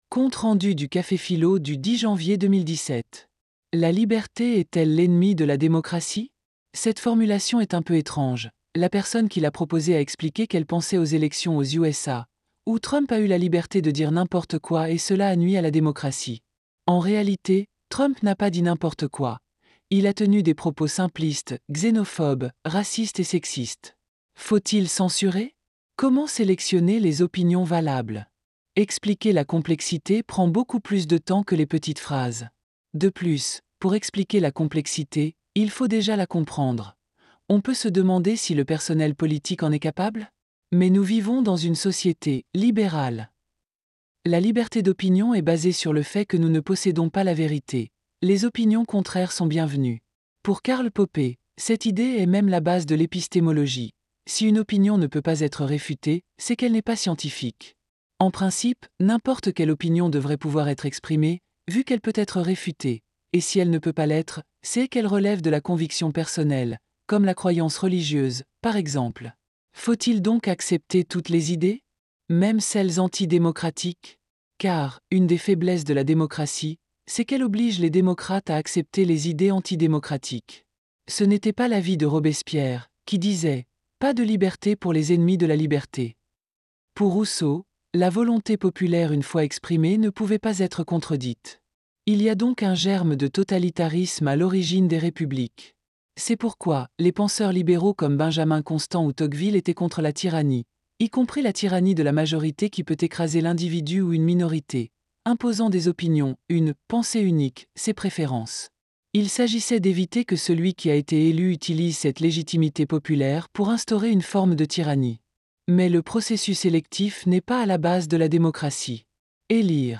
Conférences et cafés-philo, Orléans
CAFÉ-PHILO PHILOMANIA La liberté est-elle l’ennemi de la démocratie ?